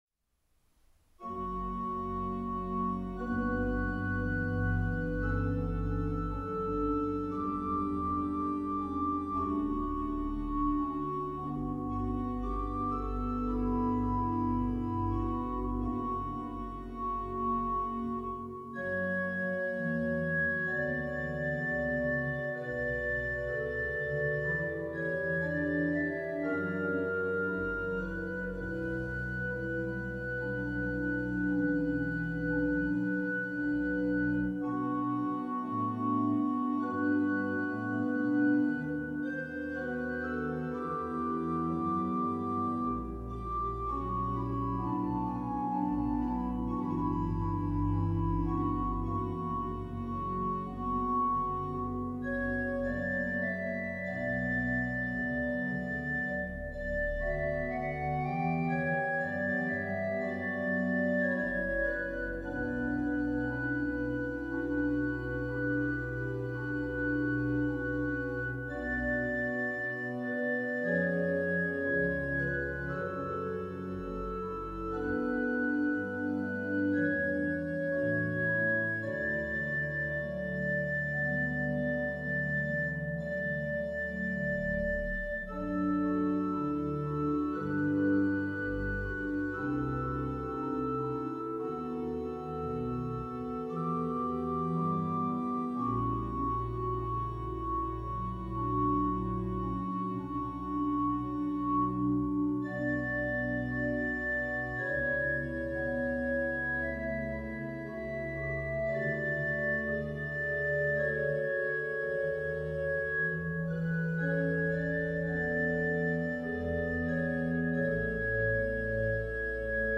Bij deze orgels zitten de luidsprekers in het bovenblad. Dit zorgt voor een ruimtelijk klankeffect.
Dit orgel heeft 9 geluidskanalen, 46 registers en 3 klavieren.